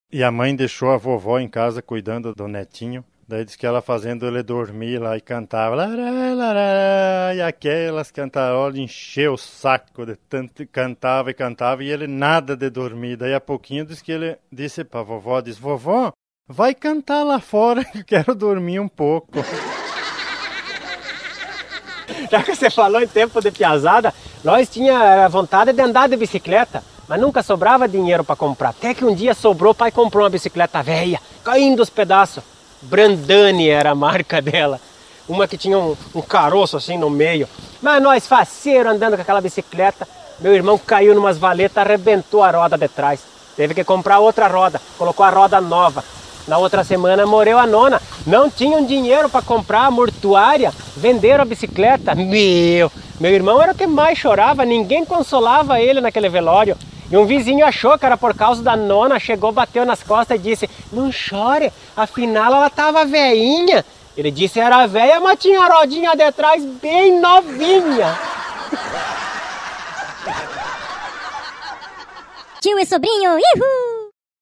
Paródia e Comédia